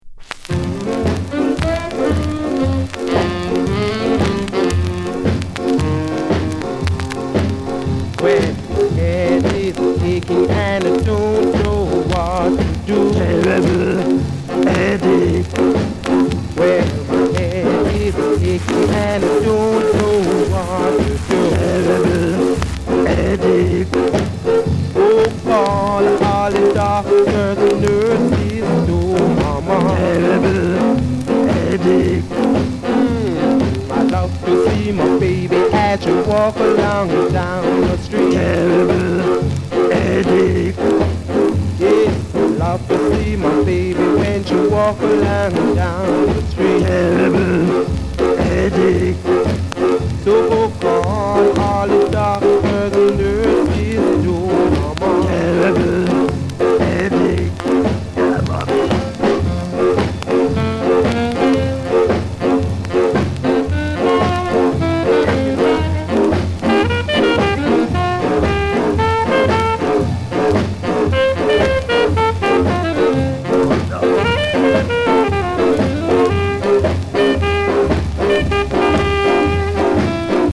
Genre: Rhythm & Blues/Ska